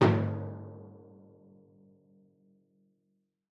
timpani7b-hit-v5-rr2-main.mp3